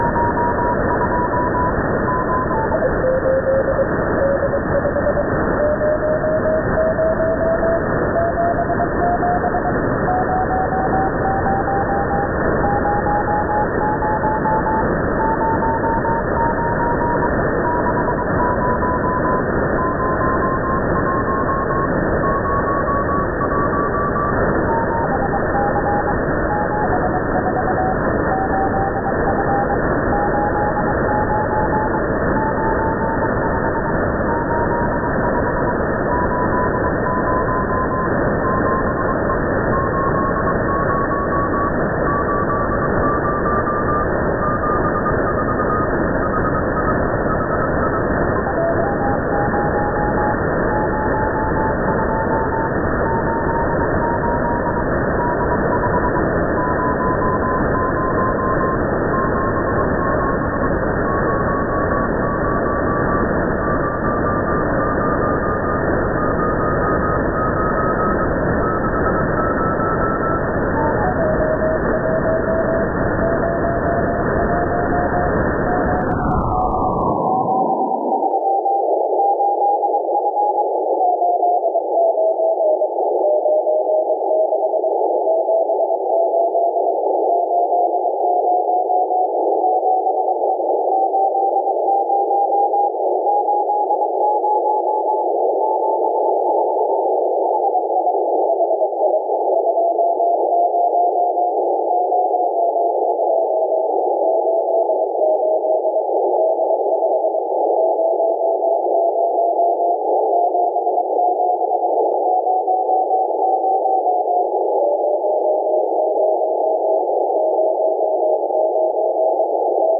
It’s got a stronger 1-watt beacon, which wasn’t too hard to spot.
Audio of FO-29’s beacon:
Despite the stronger transmitter, the received signal is weaker (probably due to being low on the horizon), and the the morse code is sufficiently fast that I’m not able to decode it by ear.